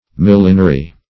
Millinery \Mil"li*ner*y\, n.